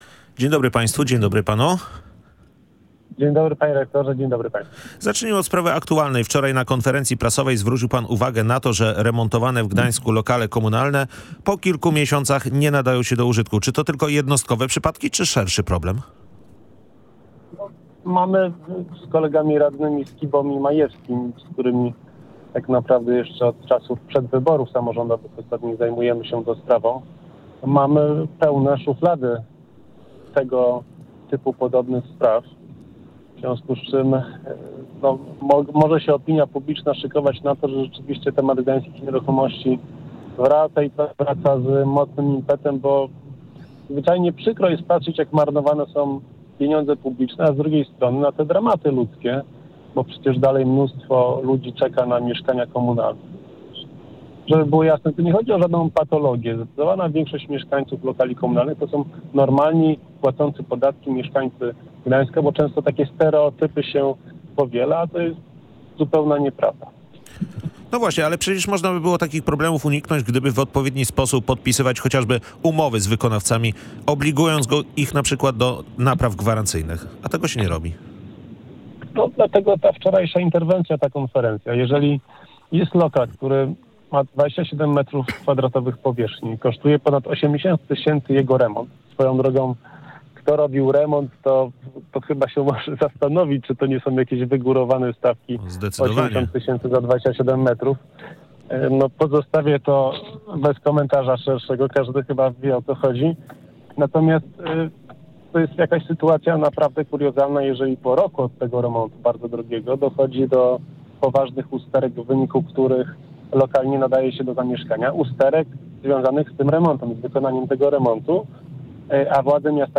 Opinia publiczna może przygotować się na to, że temat Gdańskich Nieruchomości wraca z impetem – mówi Gość Dnia Radia Gdańsk.